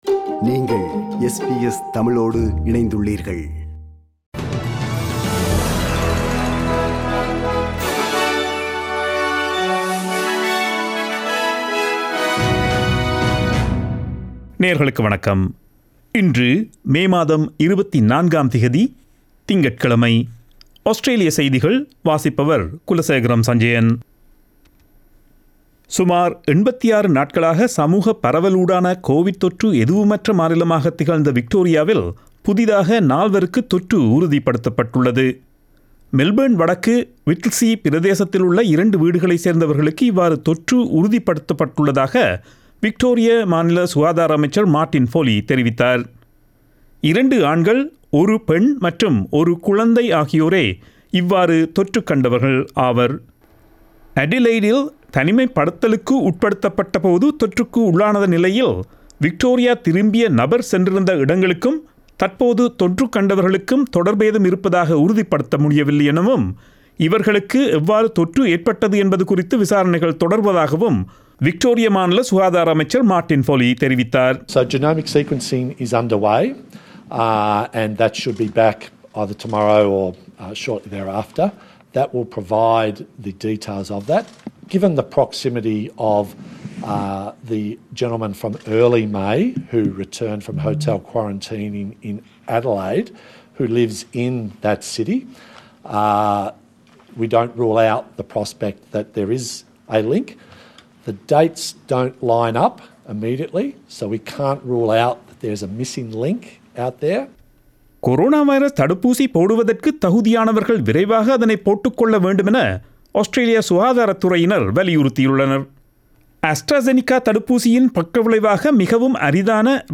Australian news bulletin for Monday 24 May 2021.